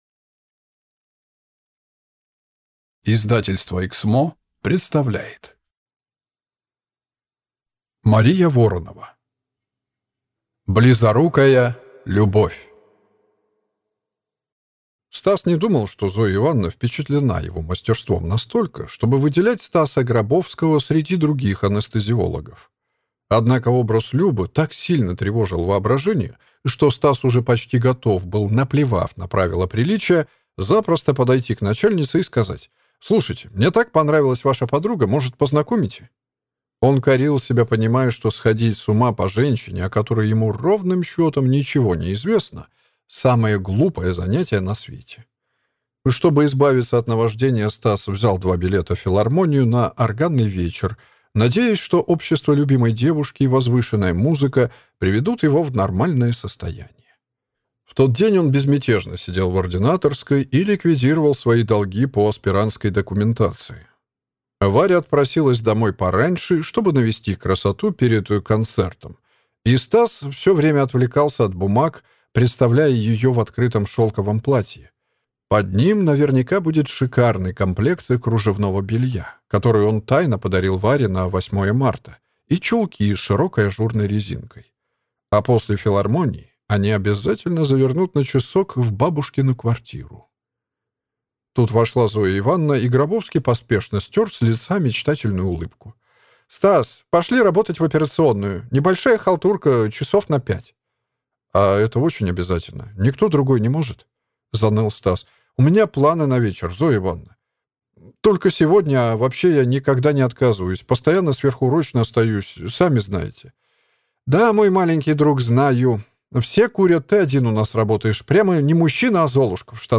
Аудиокнига Близорукая любовь | Библиотека аудиокниг